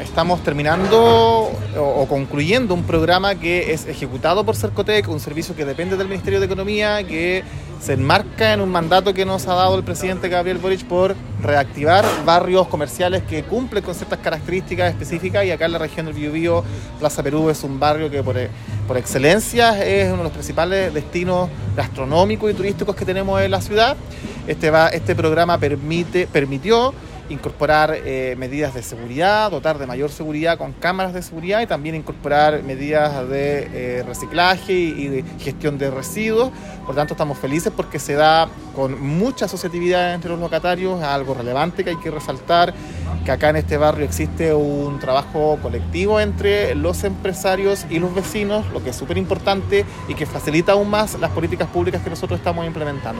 El seremi de Economía, Fomento y Turismo del Biobío, Javier Sepúlveda, destacó la relevancia del programa y su impacto en la comunidad.